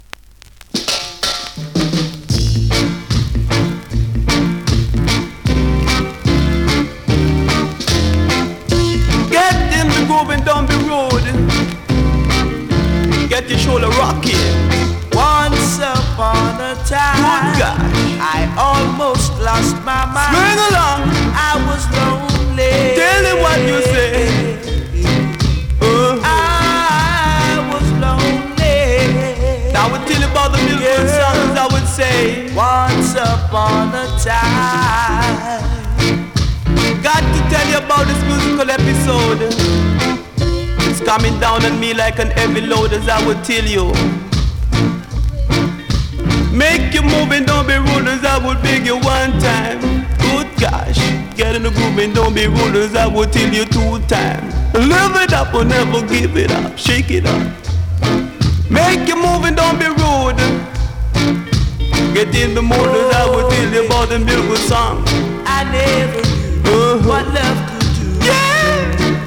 2019 NEW IN!!SKA〜REGGAE!!
スリキズ、ノイズ比較的少なめで